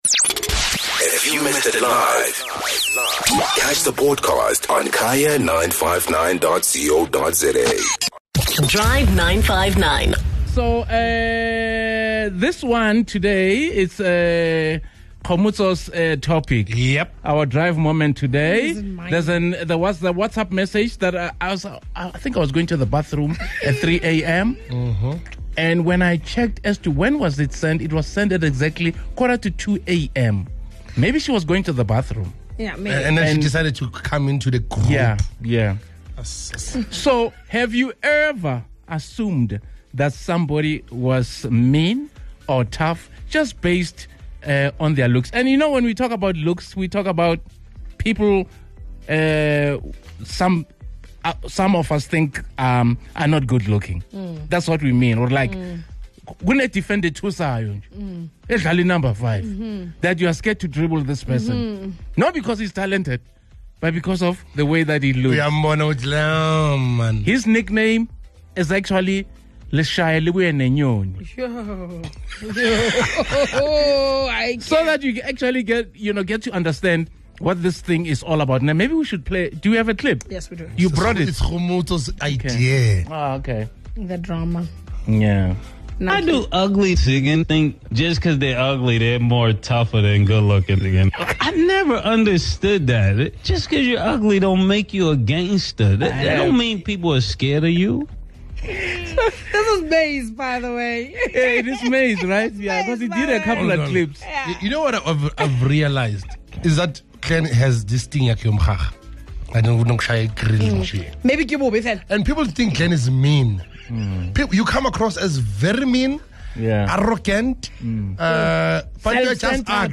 Skhumba and our listeners share their hilarious stories about looks and the perception biases we all have.